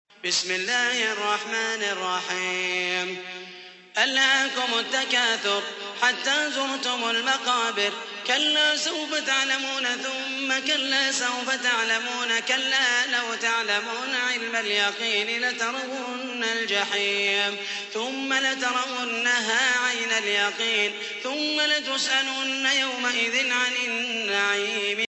تحميل : 102. سورة التكاثر / القارئ محمد المحيسني / القرآن الكريم / موقع يا حسين